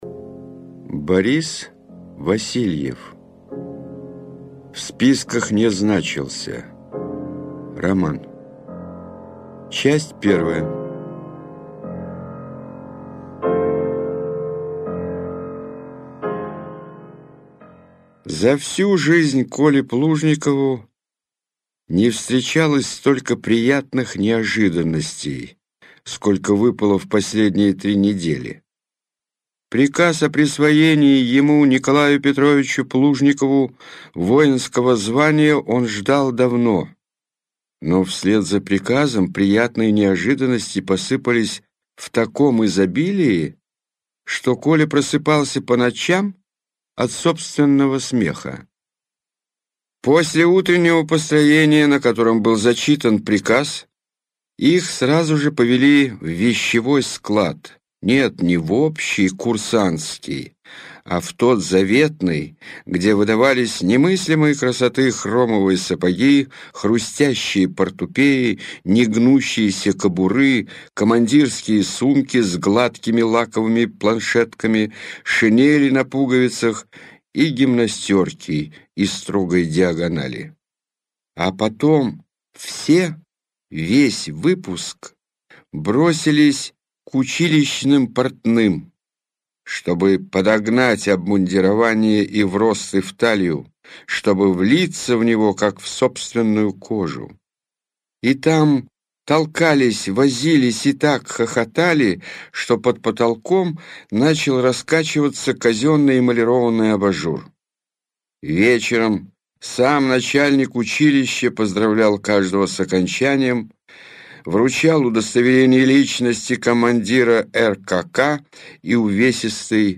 Аудиокнига В списках не значился - купить, скачать и слушать онлайн | КнигоПоиск